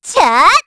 Estelle-Vox_Attack2_kr.wav